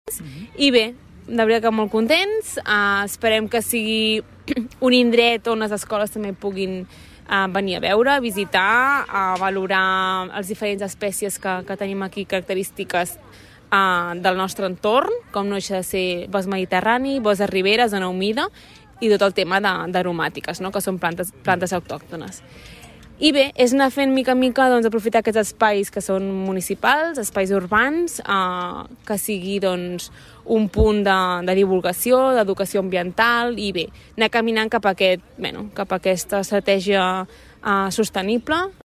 Ho detalla la regidora Vergés.